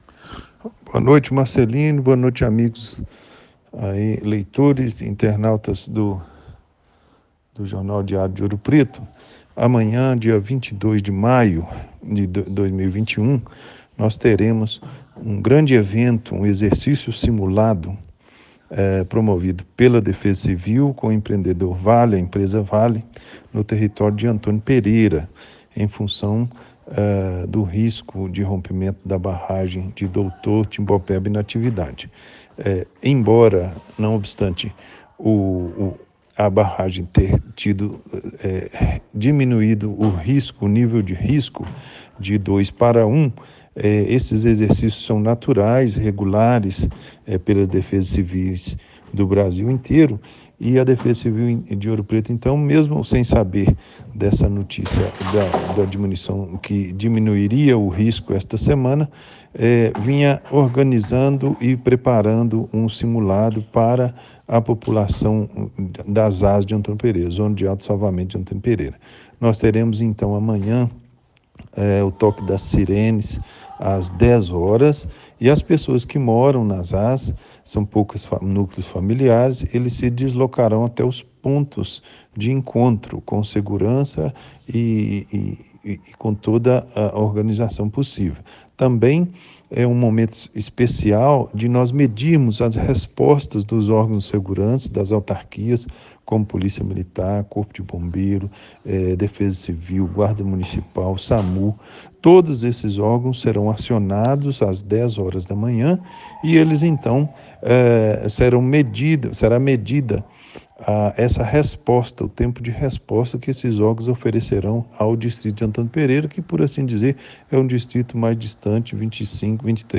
O Secretário de Defesa Social de Ouro Preto, Juscelino dos Santos Gonçalves, falou para a reportagem do diário de Ouro Preto no fim da tarde de ontem, ouçam a seguir: